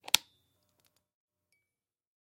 Звуки переключателя, выключателя
Механический выключатель третий вариант